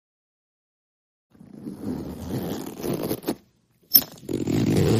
Alien Communication is a free sci-fi sound effect available for download in MP3 format.
427_alien_communication.mp3